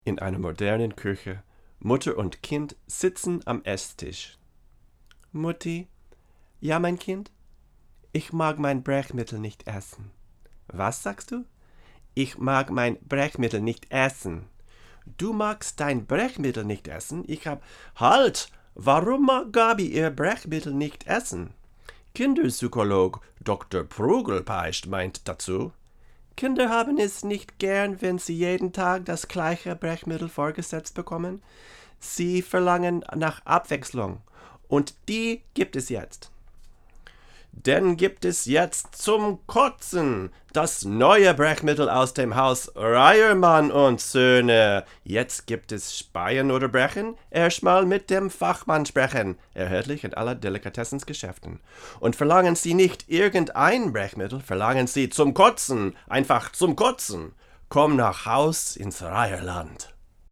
Here are some quick, 1-take MP3 sound files showing how each pattern sounds through a Presonus ADL 600 preamp into a Rosetta 200 A/D converter.
Voice English / German / Latin